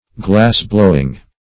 glassblowing \glass"blow*ing\, glass blowing \glass" blow*ing\n.